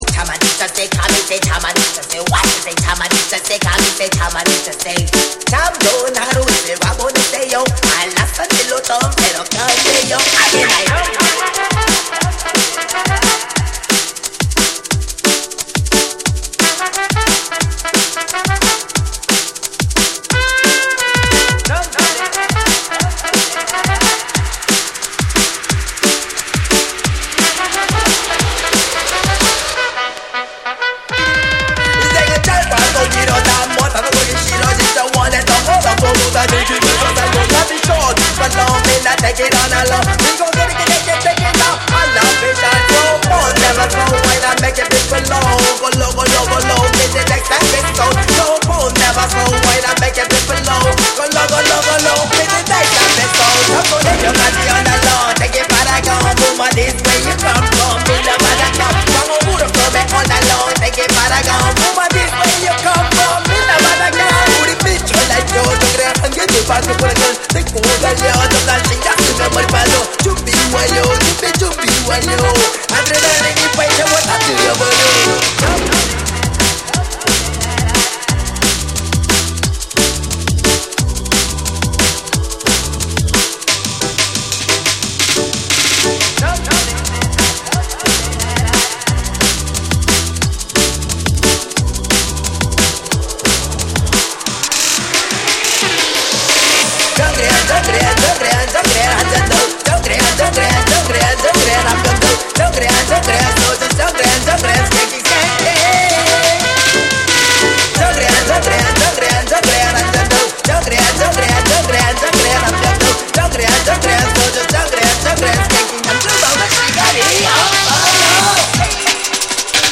即戦力間違い無しのジャングル〜ドラムンベースを収録した大推薦盤！！！
JUNGLE & DRUM'N BASS